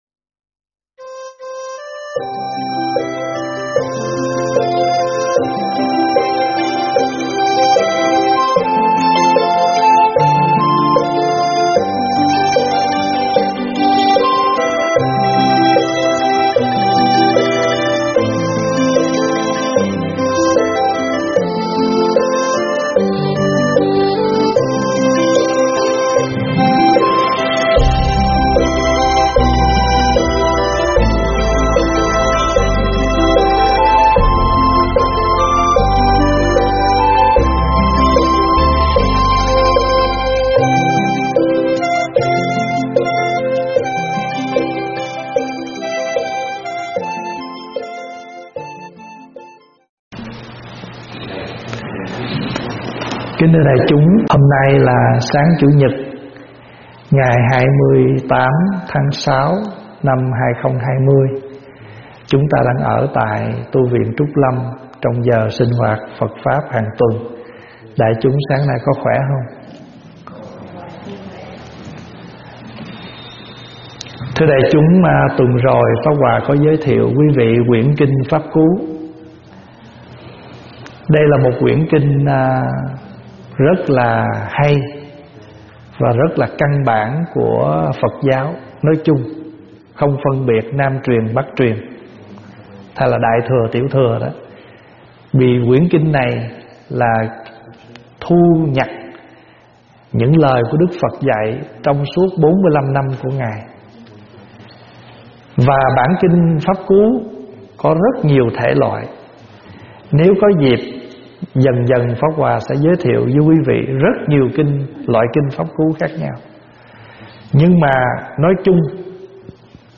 pháp thoại Muôn Sự Do Tâm
giảng tại Tv.Trúc Lâm